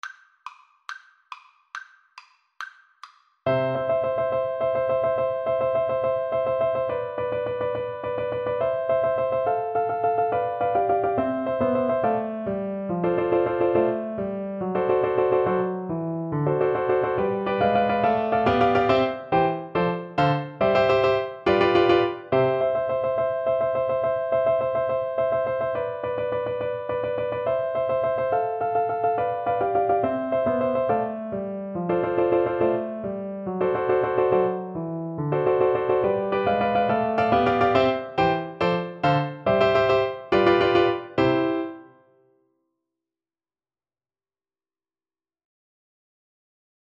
Play (or use space bar on your keyboard) Pause Music Playalong - Piano Accompaniment Playalong Band Accompaniment not yet available reset tempo print settings full screen
6/8 (View more 6/8 Music)
Allegro Vivace .=140 (View more music marked Allegro)
C major (Sounding Pitch) (View more C major Music for Recorder )
mendelssohn_italian_REC_kar1.mp3